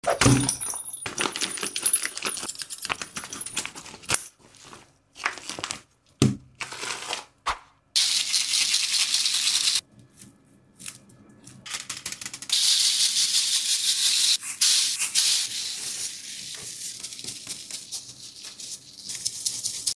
Giant Chick Sound Effects